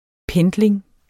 Udtale [ ˈpεndleŋ ]